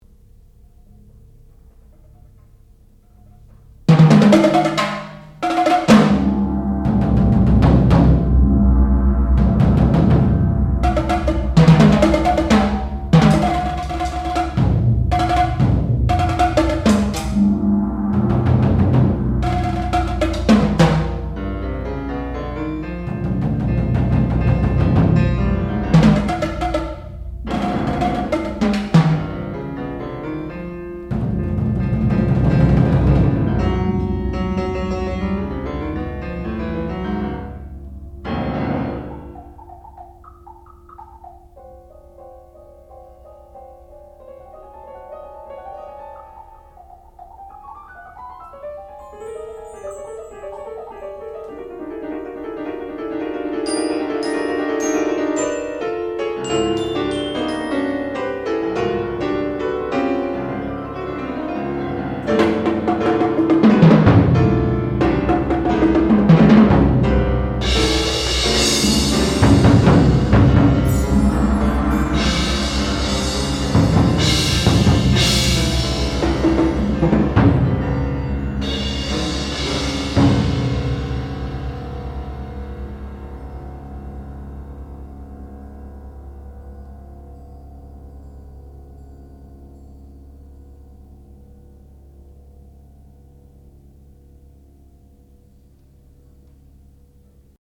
Seven Scenes for Piano and Percussion
sound recording-musical
classical music